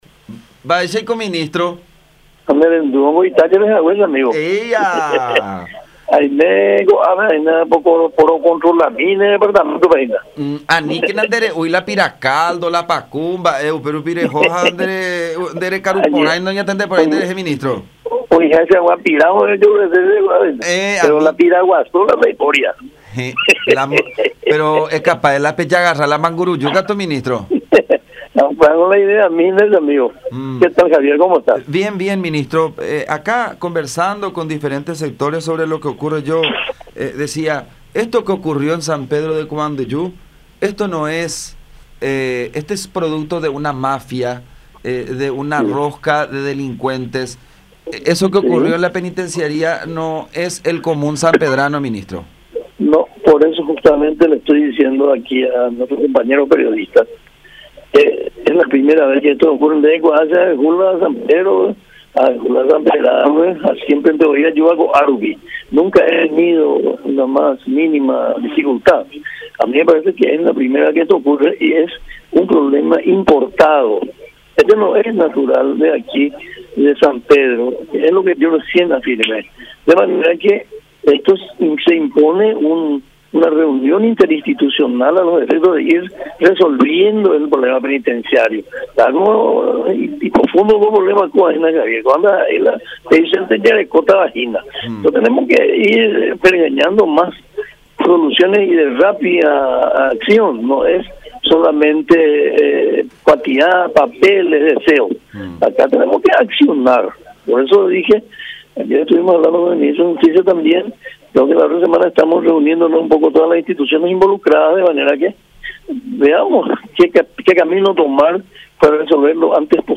“Acá la cuestión es accionar con todas las instituciones involucradas a fin de poder solucionar el problema”, aseveró en comunicación con La Unión.